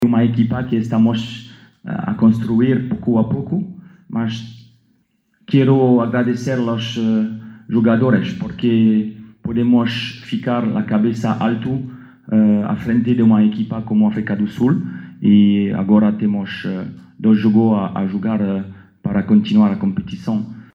O seleccionador nacional, Patrice Beaumelle, apesar da derrota, afirma que a equipa ainda tem chances de recuperação, visto que restam duas partidas no grupo.